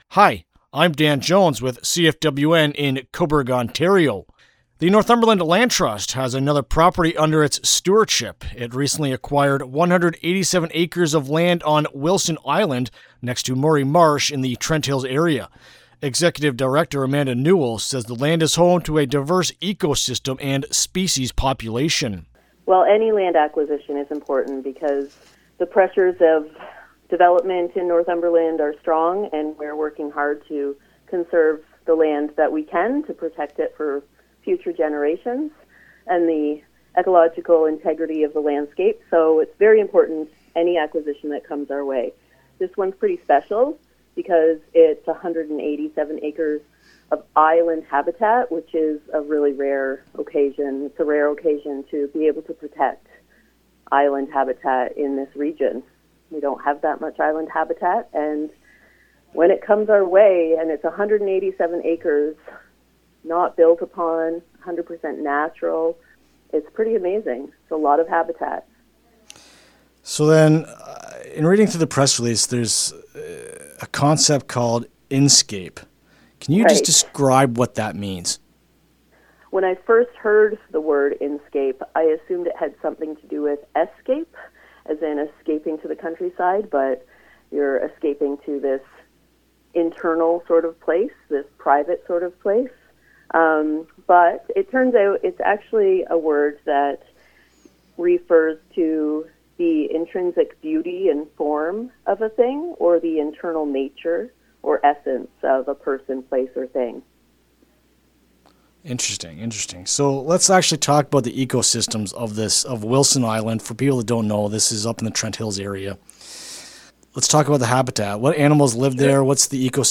NLT-Wilson-Island-Interview-LJI.mp3